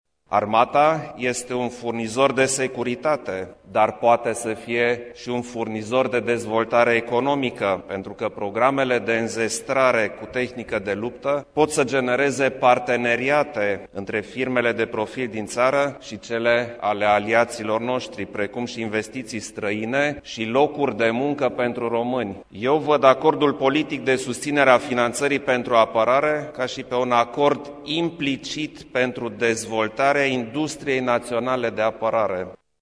Preşedintele Klaus Iohannis şi reprezentanţii formatiunilor politice din Parlament au semnat, astăzi, la Palatul Cotroceni, Acordul politic naţional privind creşterea finanţării pentru Apărare.
Prin acest acord se creează premisele pentru dezvoltarea industriei de apărare şi pentru înzestrarea armatei cu echipament modern, a spus preşedintele Iohannis: